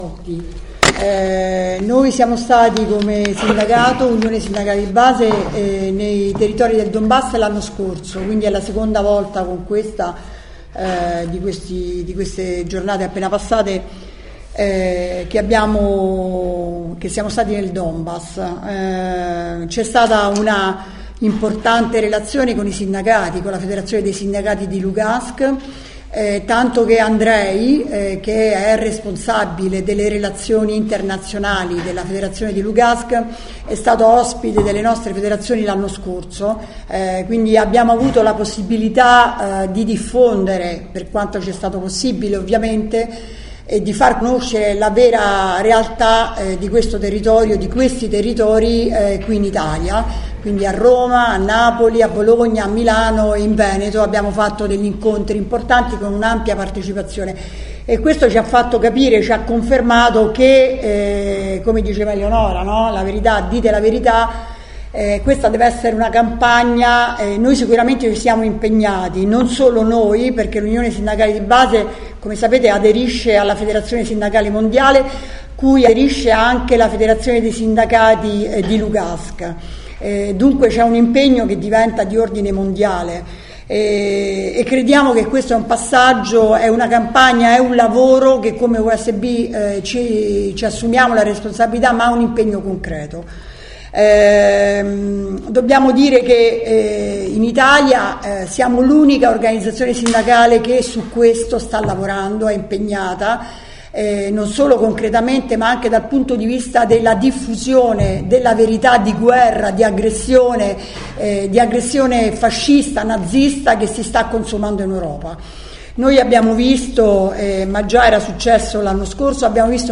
Conferenza stampa: di ritorno dal Donbass